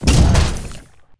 move2.wav